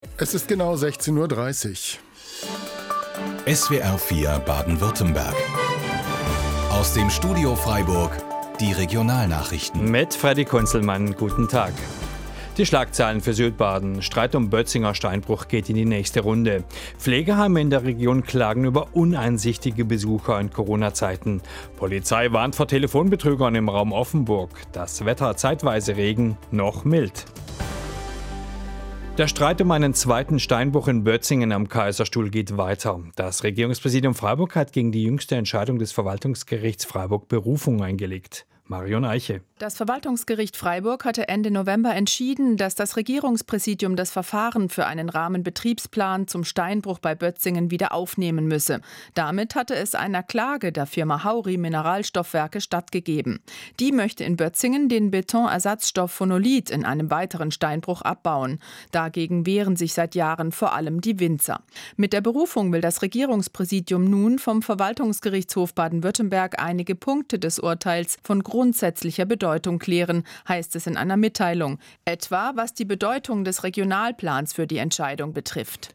SWR 4 Nachrichten zum Steinbruch